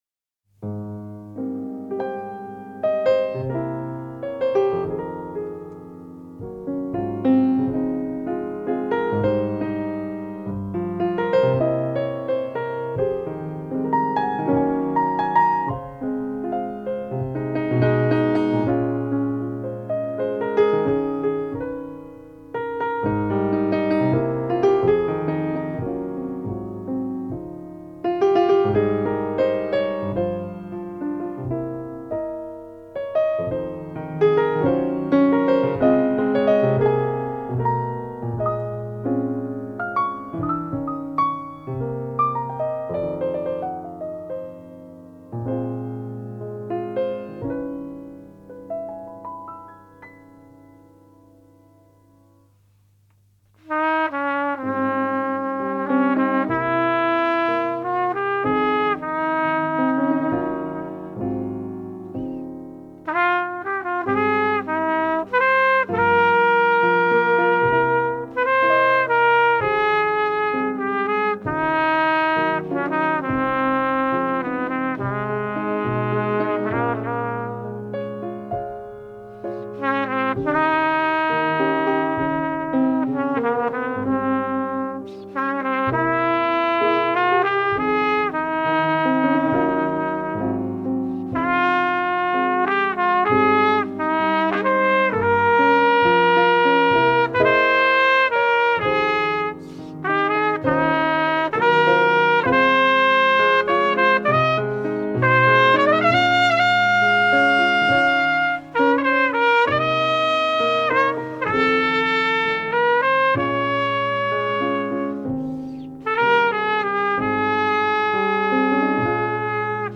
CD(Jazz)(9)